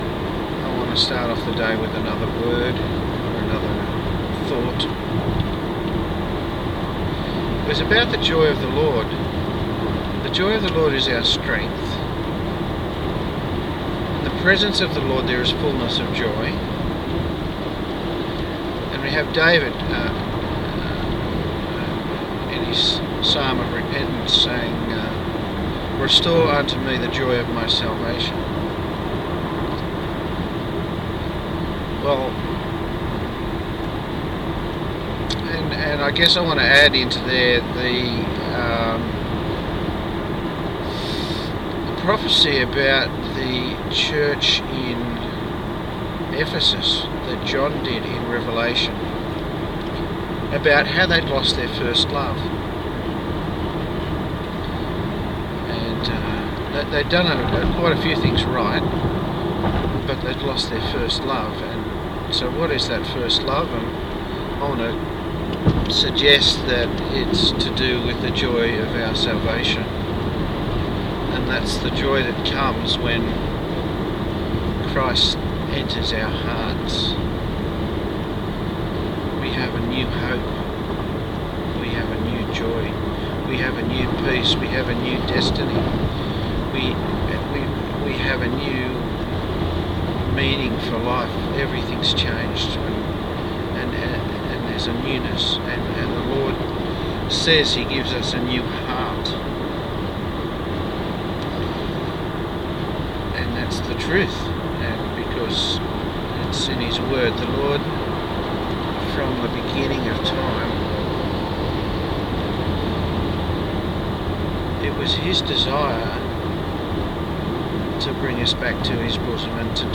You see, as I drive along, praying and worshipping the Lord, I am now capturing some of my God thoughts on audio (via my Pocket PC).
Here is another nugget, with all of the road noises as well.